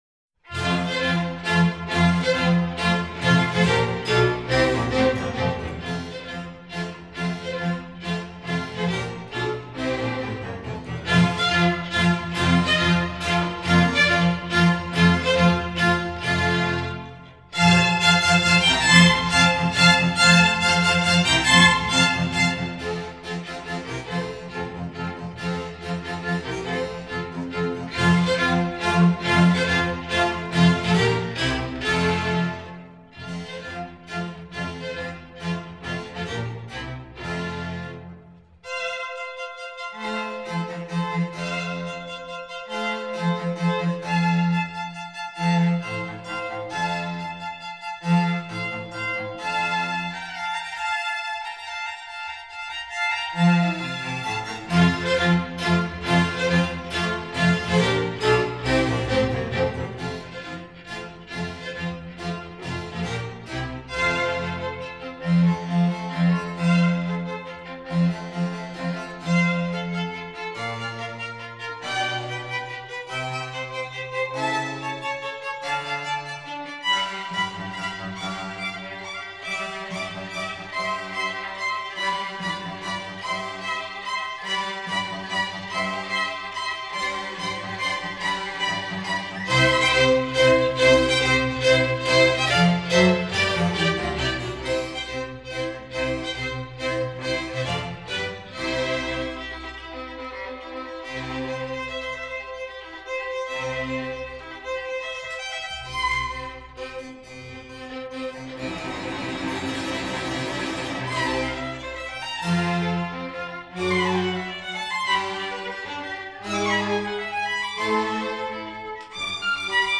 Musique narrative, descriptive, figurative.
Orchestre baroque
mesure à 3 temps
style baroque